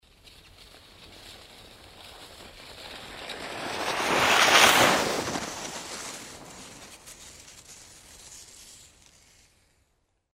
Звуки санок
Звук веселого скрипа саней